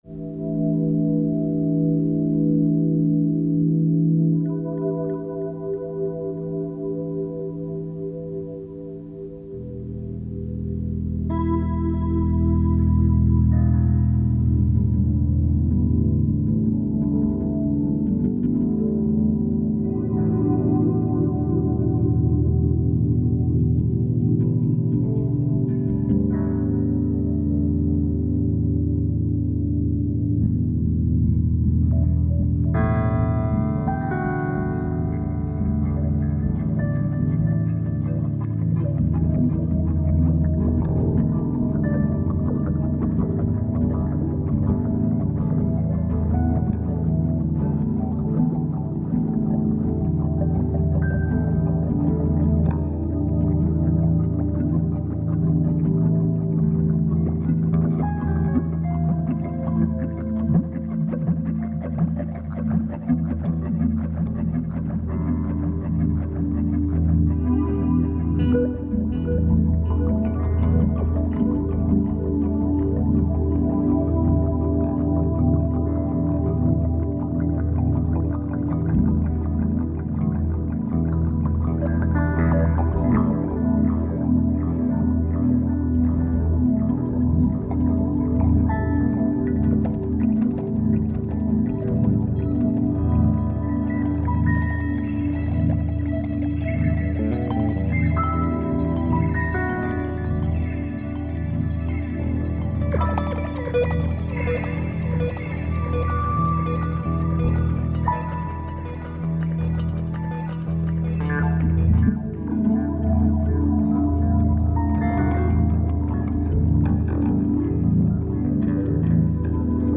Electric piano